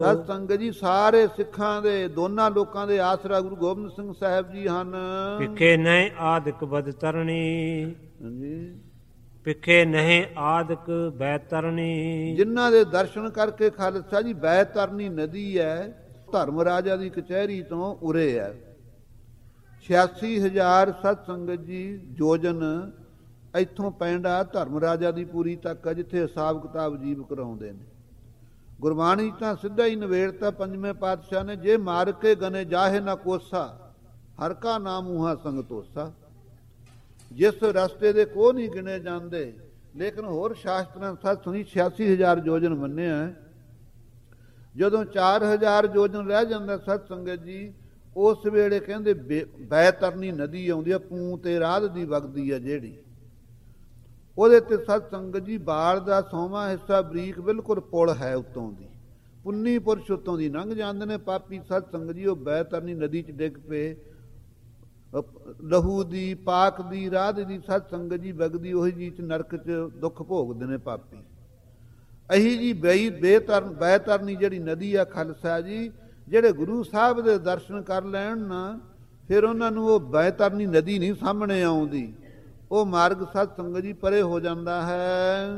This katha describes the immense difficulties faced by a person (who does not jap naam) on the journey after death.